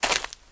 STEPS Leaves, Walk 27.wav